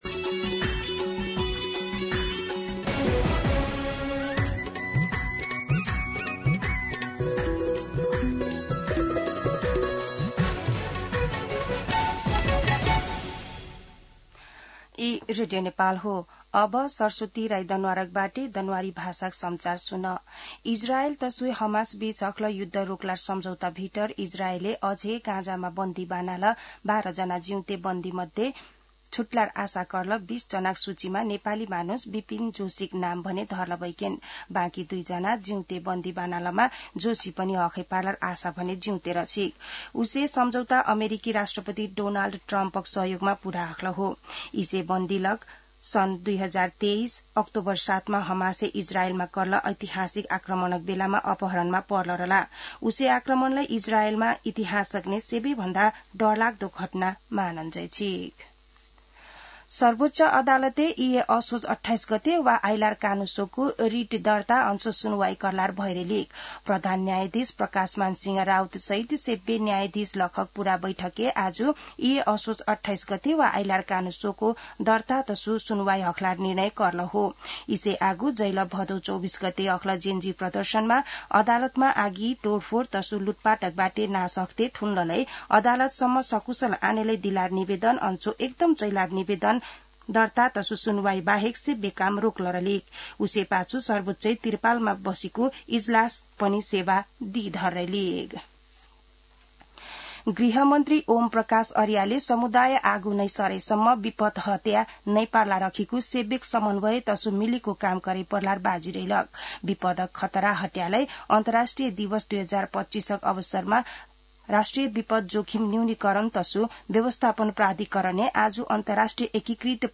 दनुवार भाषामा समाचार : २७ असोज , २०८२